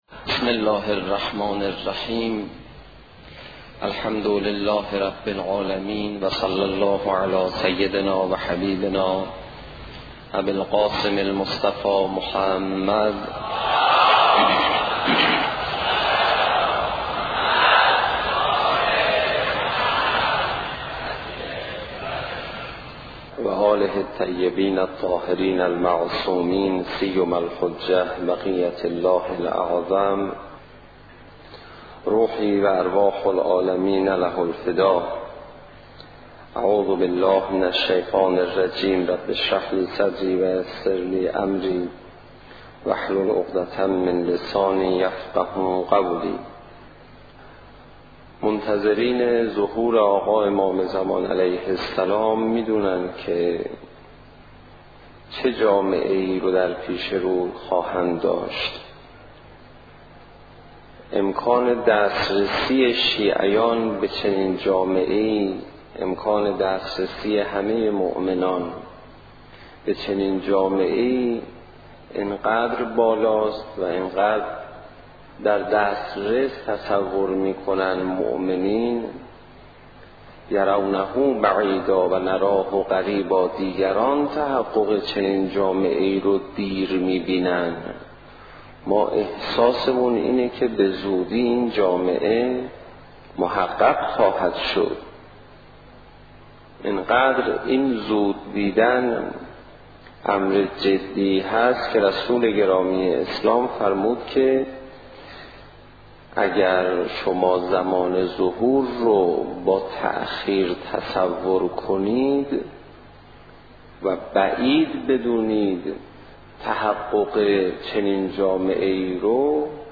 سخنرانی حجت الاسلام پناهیان درمورد آمادگی قبل و بعد از ظهور
سخنرانی حاج آقای پناهیان با موضوع انتظار و ظهور